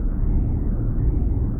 Index of /musicradar/rhythmic-inspiration-samples/150bpm